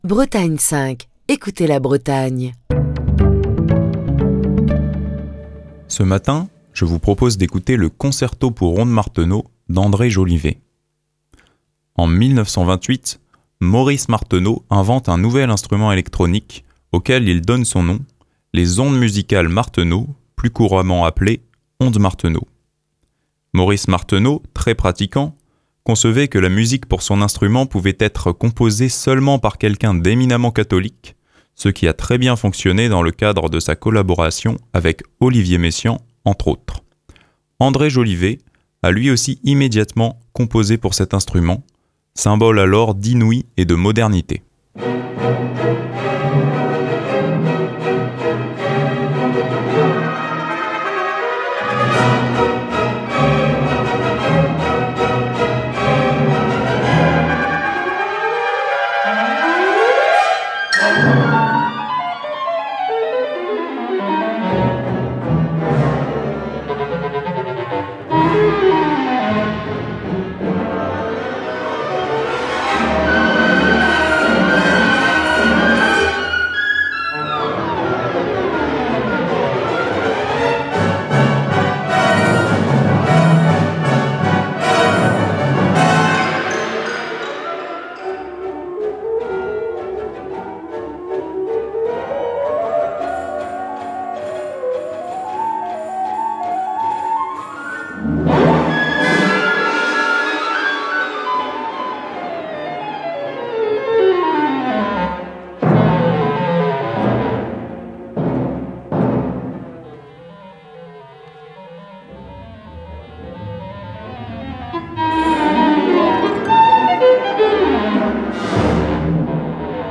dans cette version enregistrée en 1970